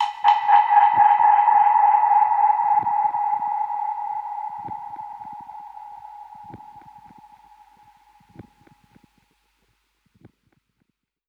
DPFX_PercHit_A_85-09.wav